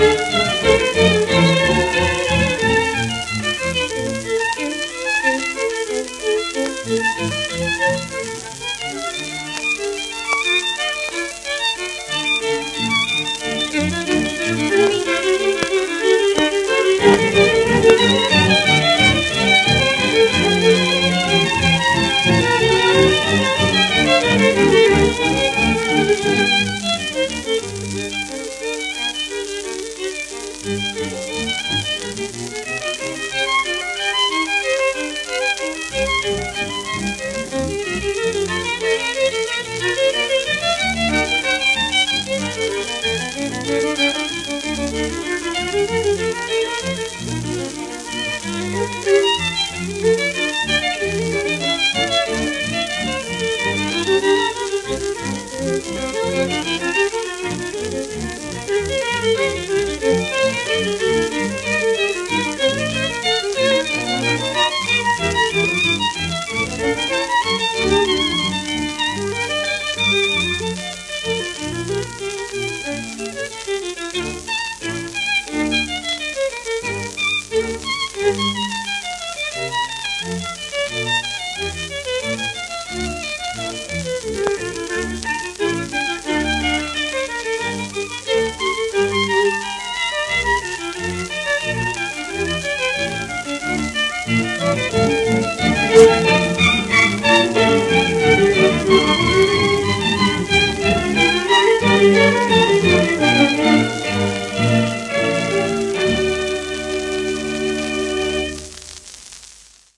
盤質A- *１面目キズ有(4~6回クリック音有り)